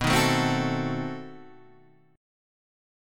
B7b9 chord {x 2 1 2 1 2} chord